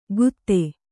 ♪ gutte